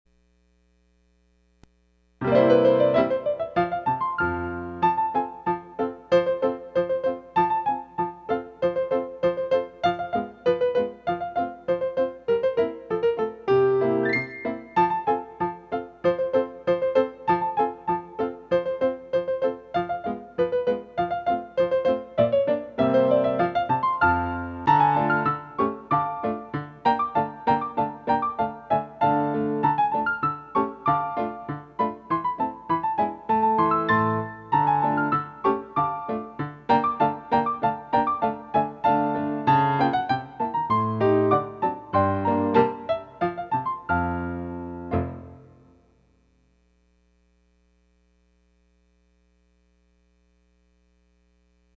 Music for Petit Allegro